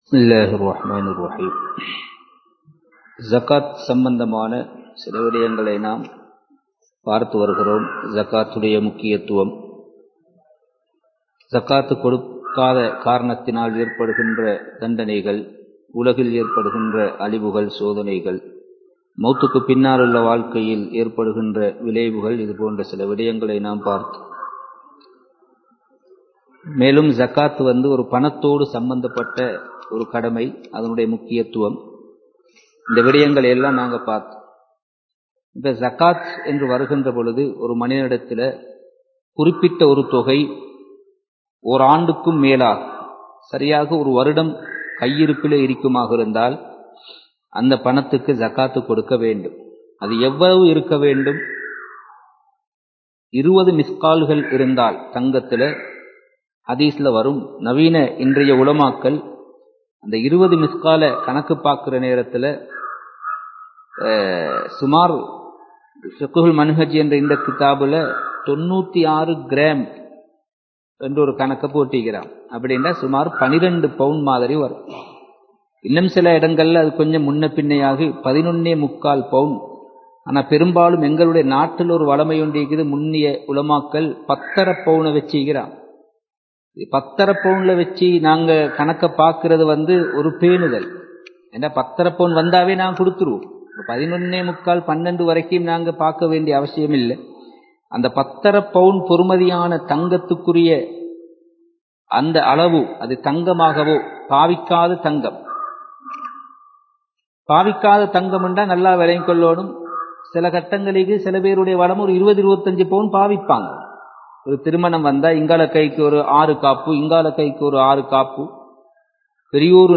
ஸகாத் (Thafseer Class 23) | Audio Bayans | All Ceylon Muslim Youth Community | Addalaichenai
Kandy, Kattukela Jumua Masjith